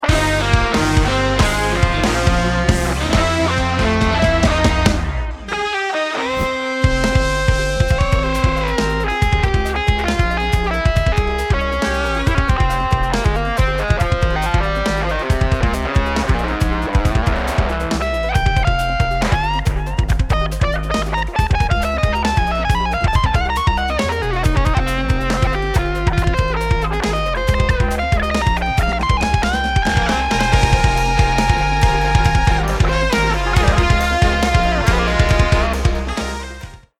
A guitar solo in 7/4
I stuck with my trusty electric guitar for this.